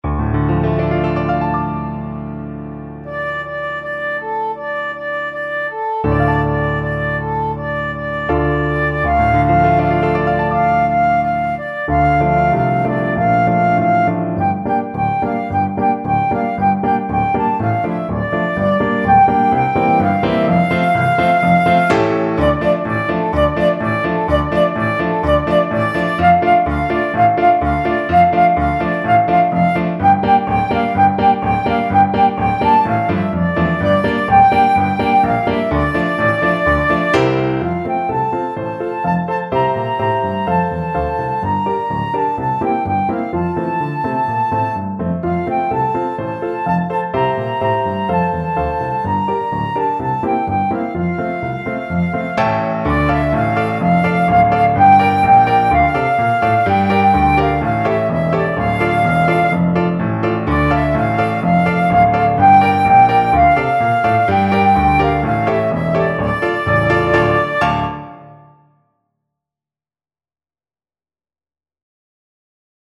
Flute
Traditional Music of unknown author.
2/2 (View more 2/2 Music)
Andante =c.80
D minor (Sounding Pitch) (View more D minor Music for Flute )